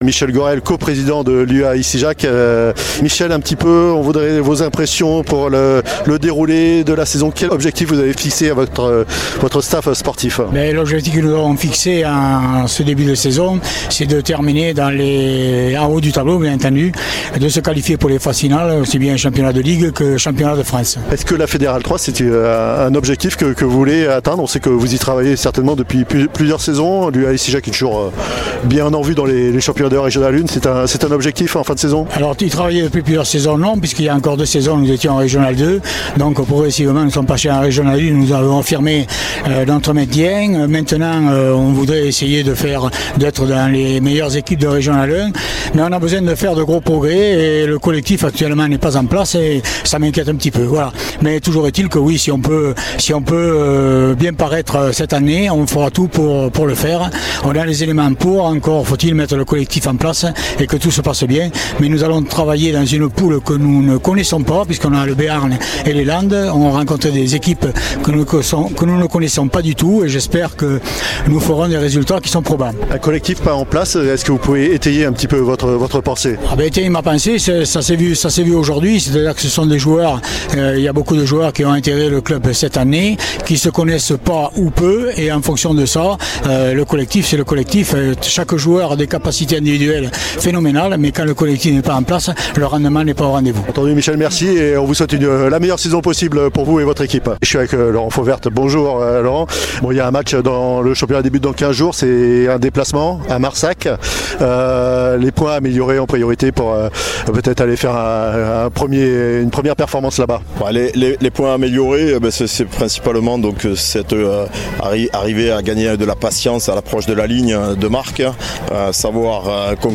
Stade Foyen - UAI : Interviews